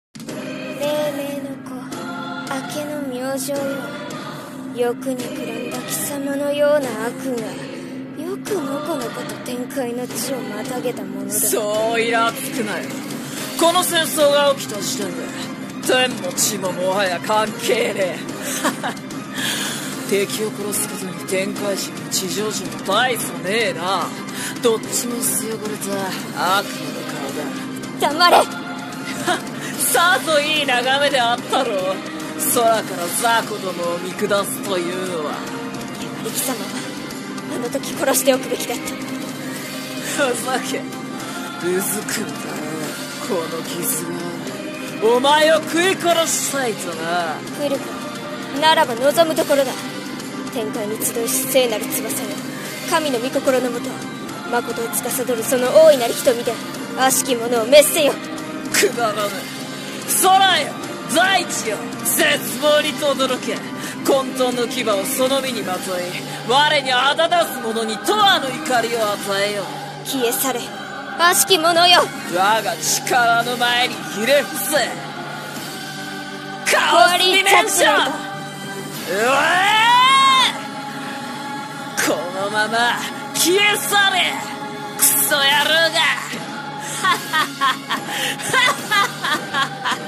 【声劇台本】天と地【掛け合い】